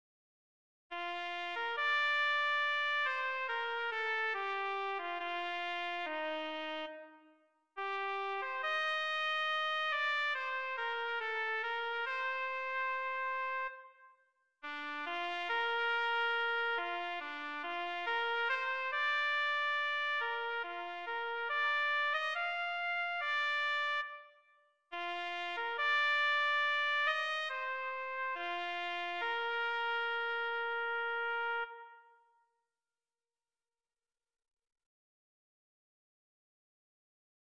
Hejnał już w sobotę